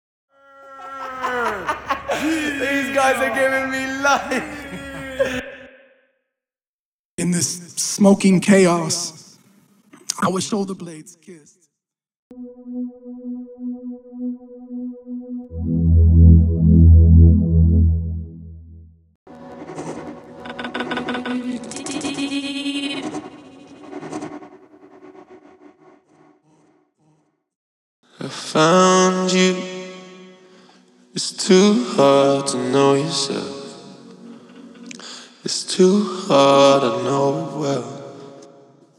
(Studio Backing Noise Stem)
(Studio Ipad Texture Stem)
(Studio Kick Stem)
(Studio Pads Stem)
(Studio Synths In A Loop Stem)